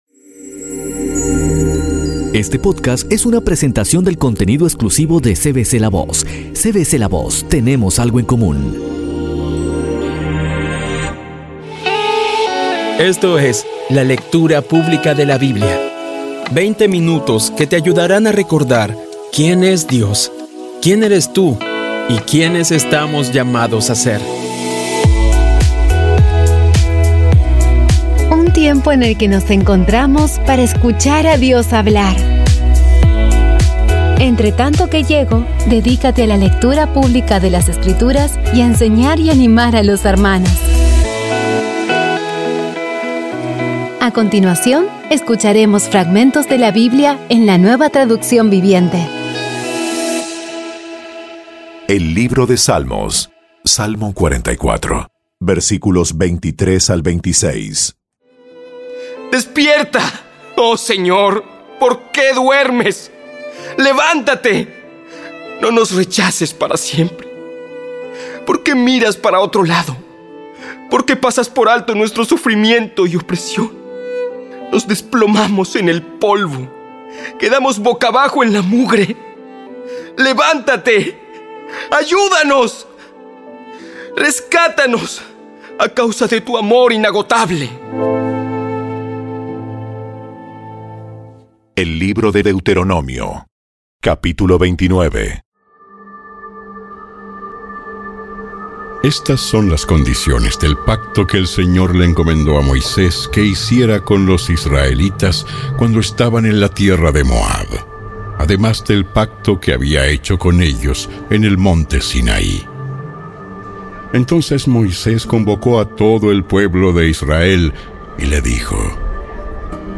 Audio Biblia Dramatizada Episodio 99
Poco a poco y con las maravillosas voces actuadas de los protagonistas vas degustando las palabras de esa guía que Dios nos dio.